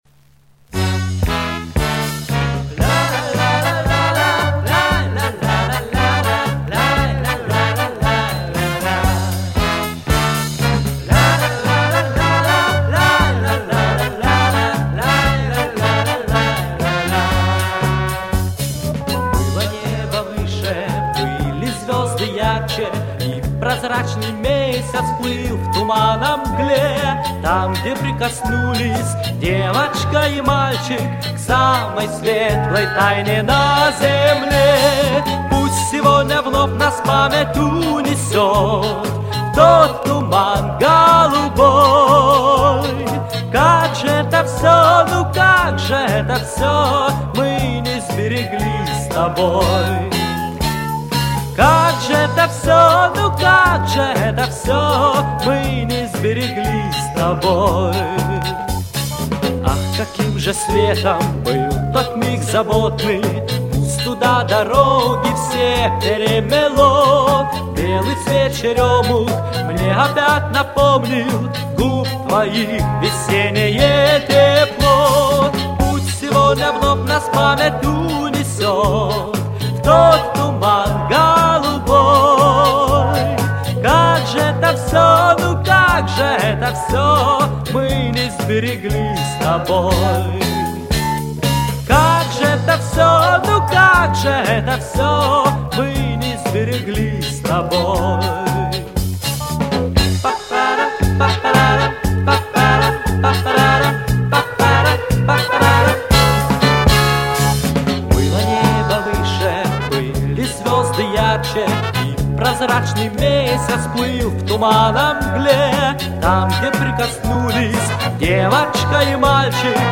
вокал